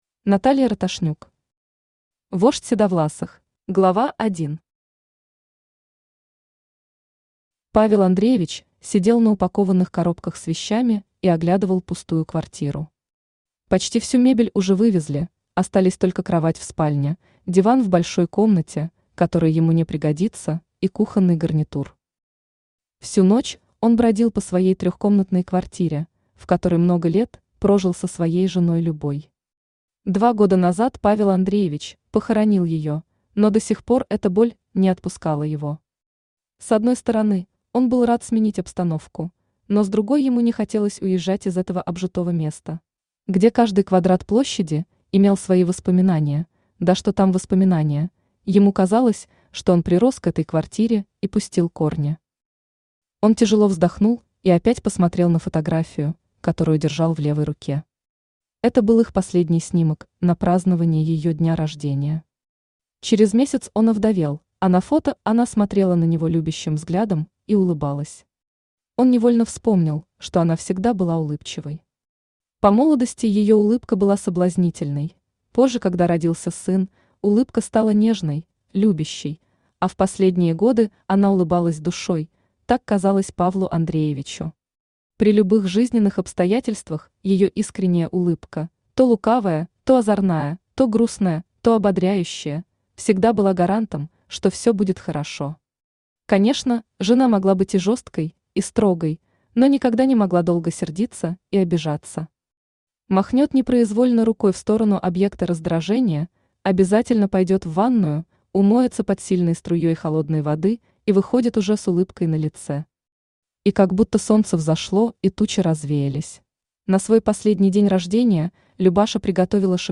Аудиокнига Вождь седовласых | Библиотека аудиокниг
Aудиокнига Вождь седовласых Автор Наталья Викторовна Роташнюк Читает аудиокнигу Авточтец ЛитРес.